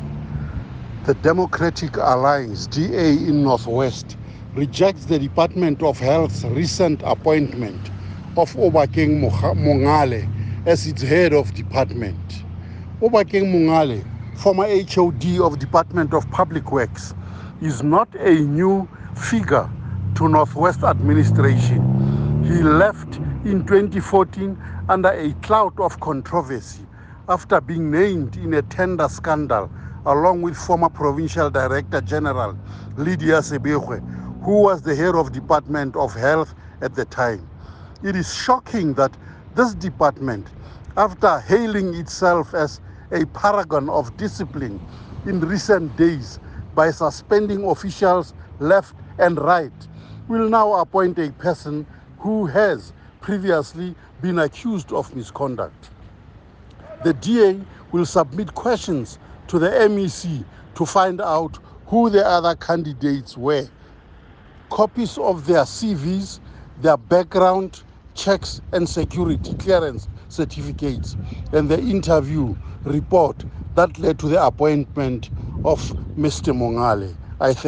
Note to Editors: Find attached soundbites in
English by DA North West Member of the Health Portfolio Committee, Winston Rabotapi.